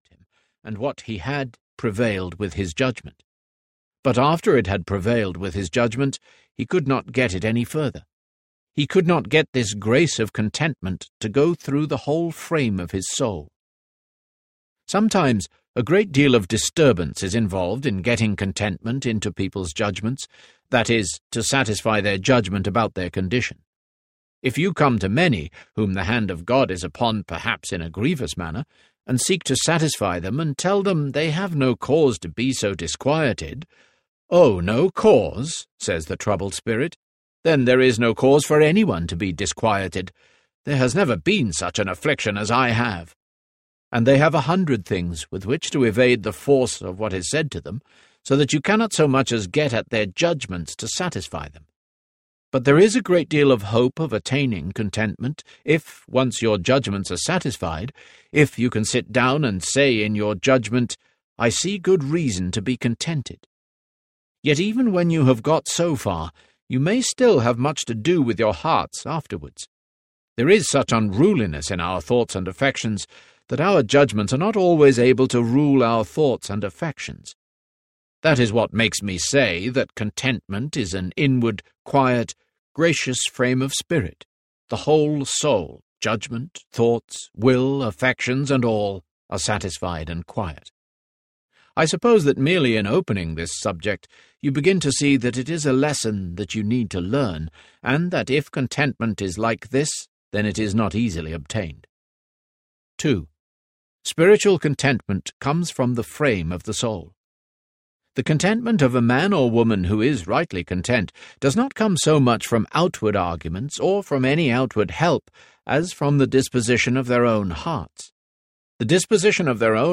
The Rare Jewel of Christian Contentment Audiobook
Narrator
8.43 Hrs. – Unabridged